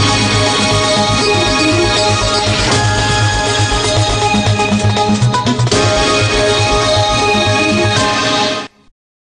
Voilà. La stéréo est mise, et amélioration de l'ensemble.
Décro stéréo 495 Ko